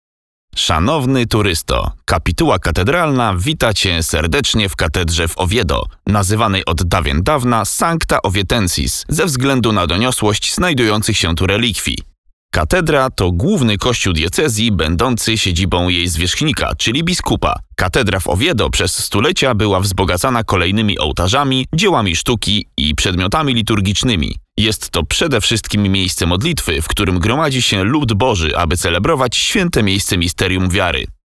Kommerziell, Tief, Natürlich, Zuverlässig, Freundlich
Audioguide
Flexible, energetic and charismatic voice.